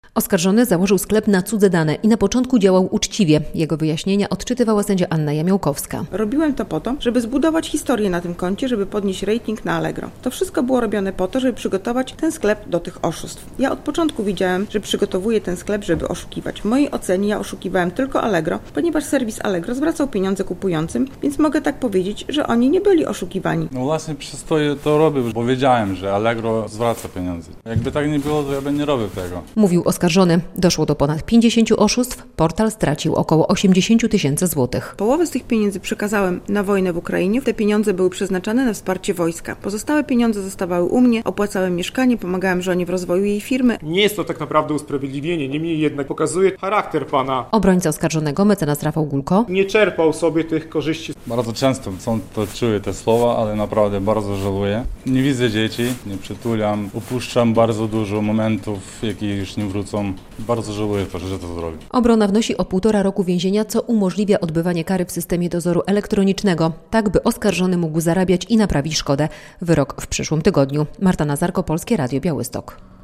Przed białostockim sądem zakończył się proces w sprawie oszustw na jednym z portali aukcyjnych - relacja